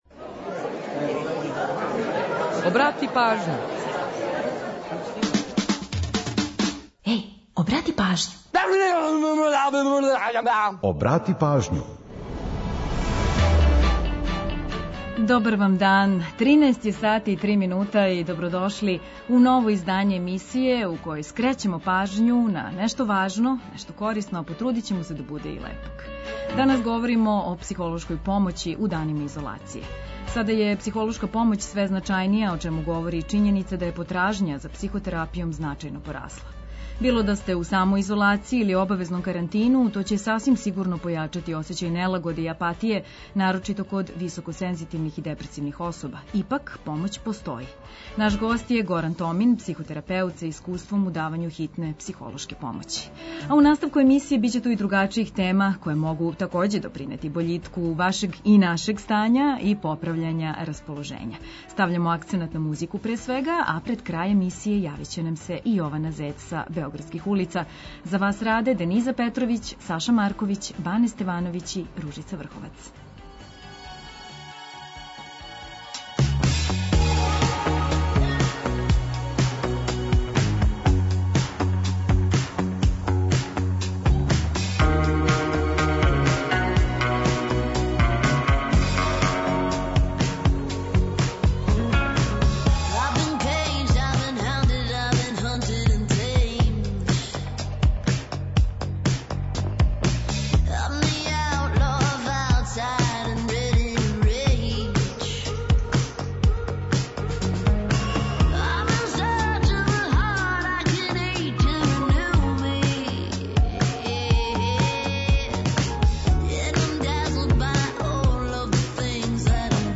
Pored saveta za kvalitetniji život u uslovima opšte napetosti i iščekivanja, raspoloženje vam podižemo dobrom muzikom, korisnim informacijama, pripremamo „Priče o pesmama” i zanimljivosti iz sveta muzike koje su obeležile 18. mart.